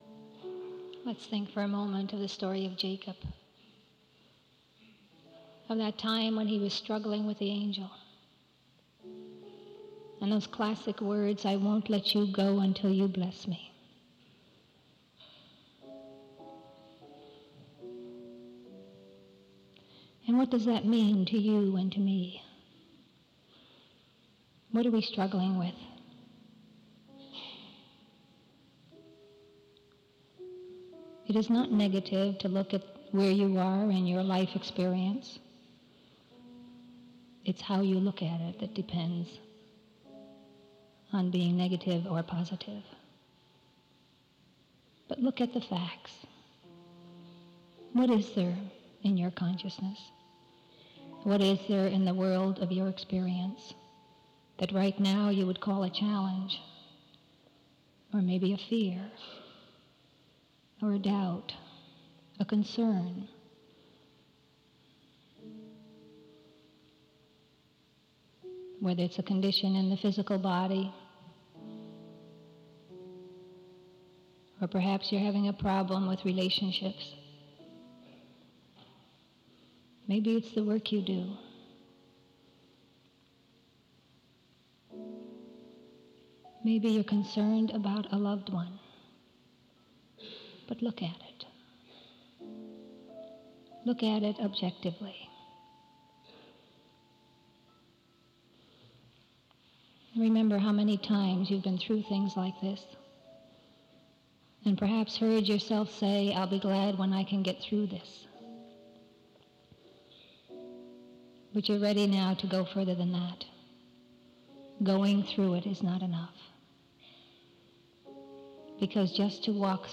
speaking on The Gospel Truth -3- An End to Dogma Eat Dogma